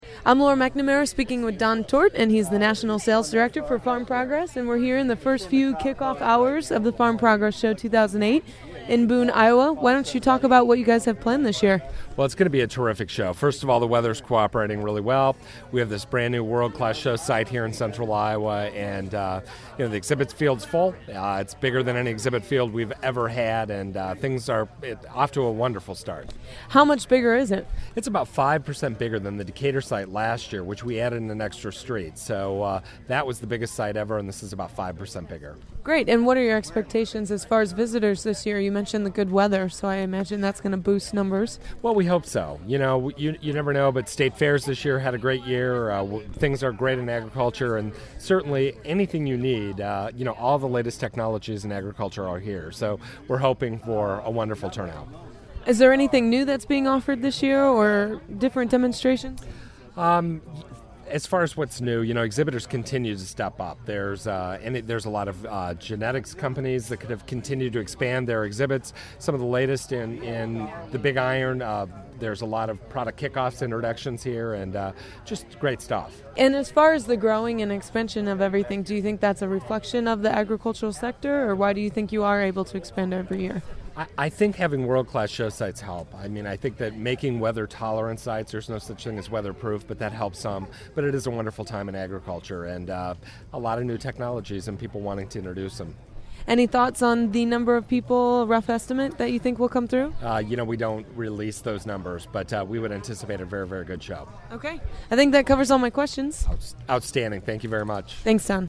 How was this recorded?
AgWired coverage of the 2008 Farm Progress Show